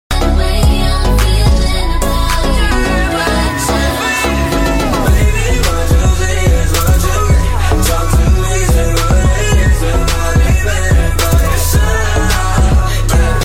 obsessed with his aussie accent😩 sound effects free download